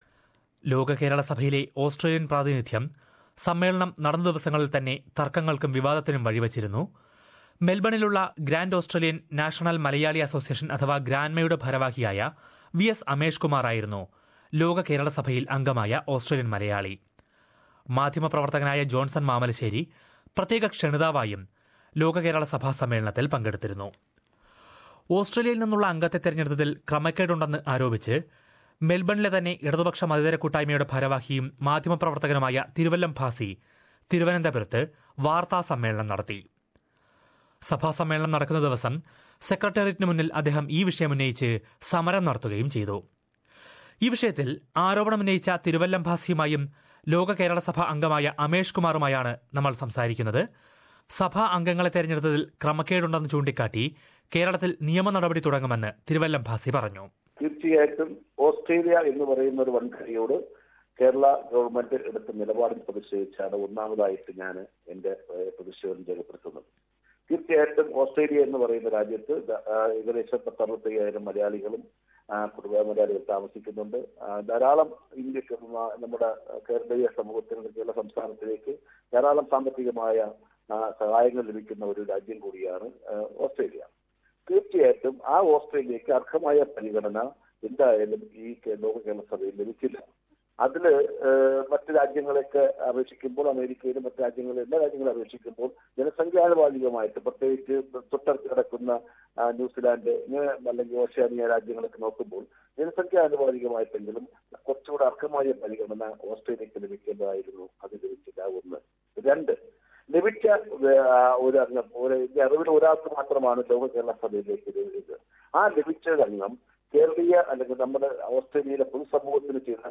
Lok Kerala Sabha - the very first conference for Non-Resident Keralites landed in controversy after complaints were made against the selection of members from Australia. Listen to this report